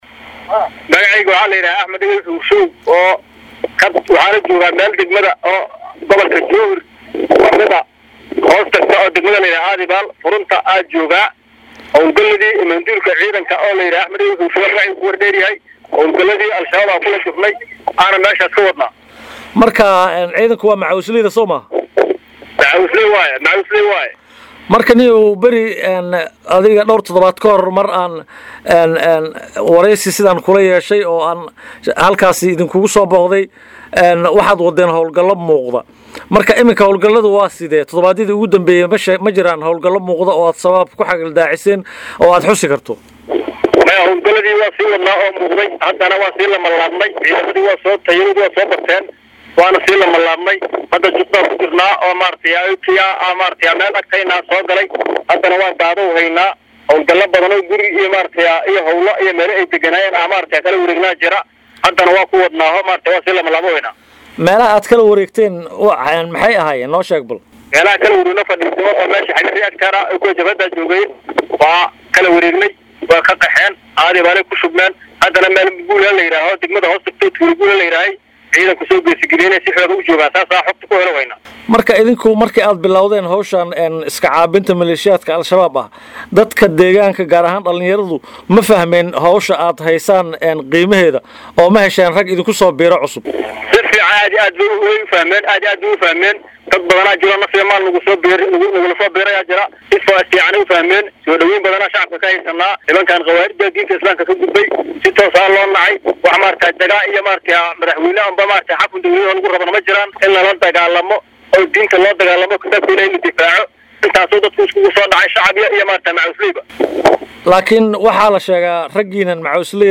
Waa sii xoogaysanaya Abaabulka ay samaynayaan dadka ku nool deegaano ka tirsan Gobalka Shabeellaha Dhexe, kaas oo ay kula dagaalamayaan Maleeshiyaadka Argagixisada ah ee Al-Shabaab. Related posts Wasiirka Maaliyadda XFS oo khudbad ka jeediyay kulanka Bangiga Dunida ee Washington April 17, 2026 Mas’uuliyiinta Buuhoodle oo u istaagay ciribtirka muqaadaraadka April 17, 2026 Mid ka mid ah Kooxdaan is abaabushay ee shacabka ah oo waraysi gaar ah siiyay Radio Muqdisho ayaa sheegay in haatan danta ay ku qasabtay dadka ku nool deegaanada qaar ee Gobalka Shabeellaha Dhexe inay Hubka wada qaataan ka dib Markii Argagixisada Al-Shabaab ay sii kordhiyeen Baada iyo Boobka ay ku haayaan shacabka.
WAREYSI-MACAWISLEEY-20-FEB-2018.mp3